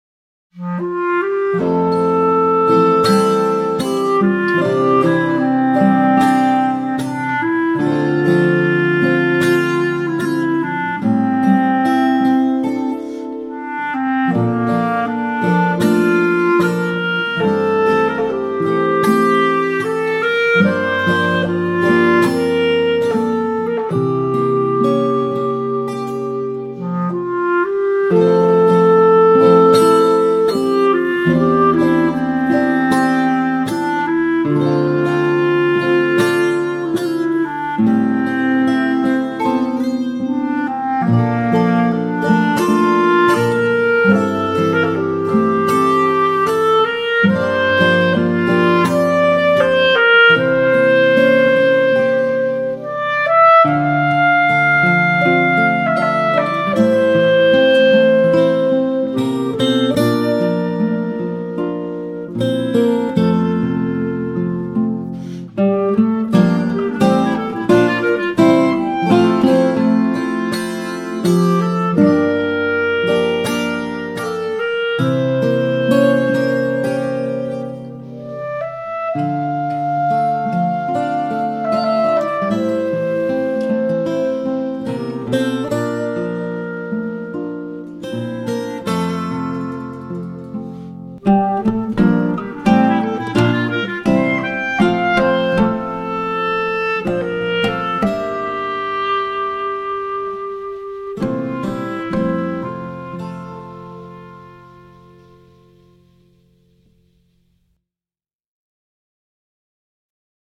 Danza